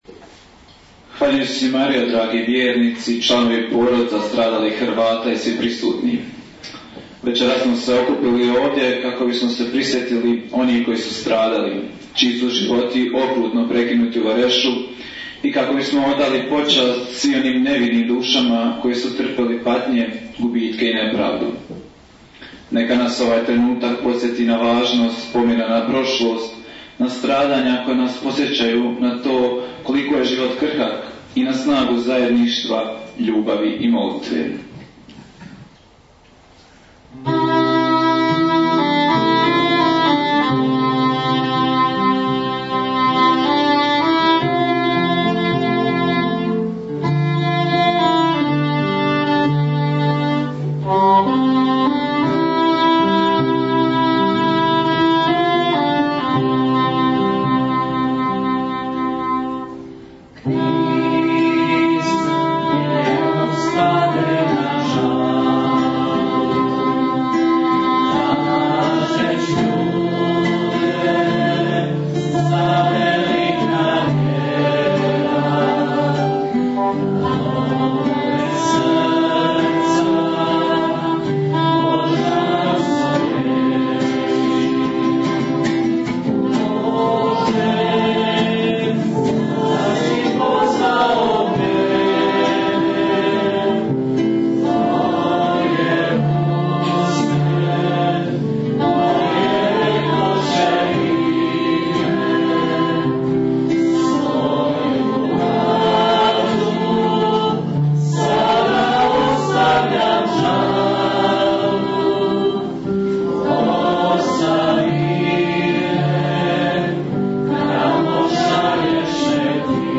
Poslušajte tonski zapis Večeri sjećanja na 32. obljetnciu stradanja Hrvata Vareša koja je održana u Staroj crkvi u Varešu 02.11.2025. godine ...